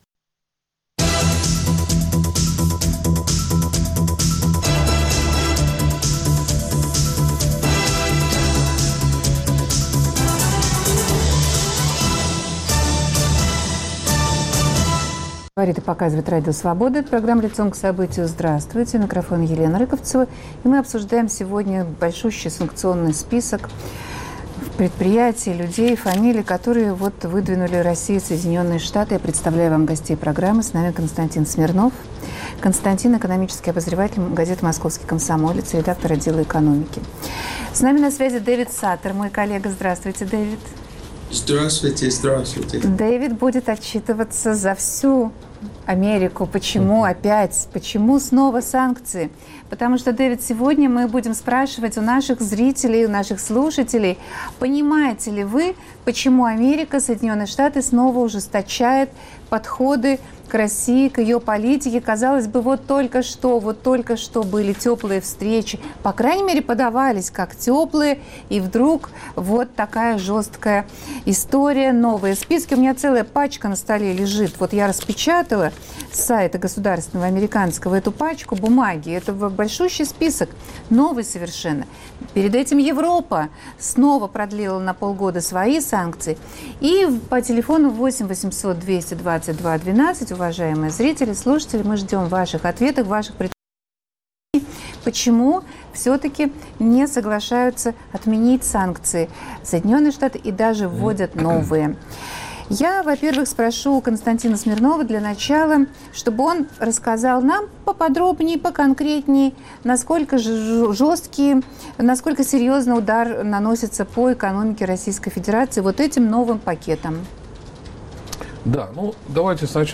Понимают ли россияне, почему Европа продлила санкции против РФ, а США еще и ввели дополнительные? За что наказывают Россию, и кого конкретно в России наказывают. Обсуждают журналисты